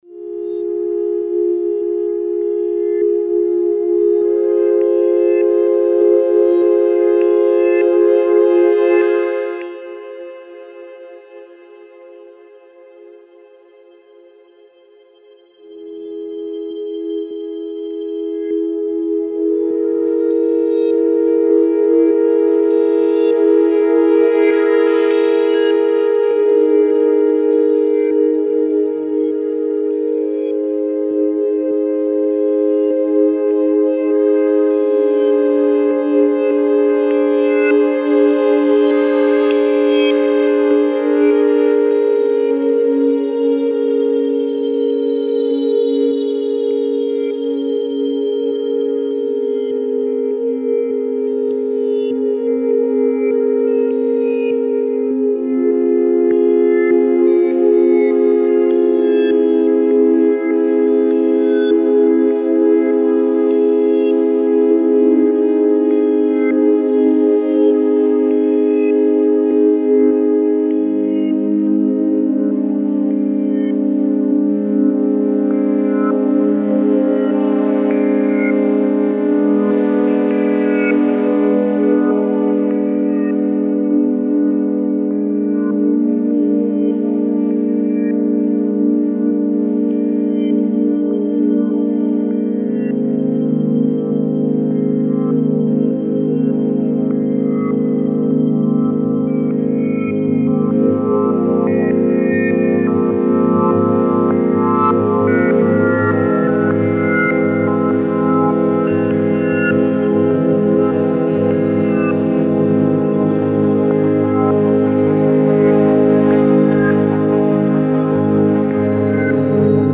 It was the ASM Hydrasynth.
A live, multi-tracked (each a single take) improv performance using only the ASM Hydrasynth.